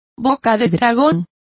Complete with pronunciation of the translation of snapdragon.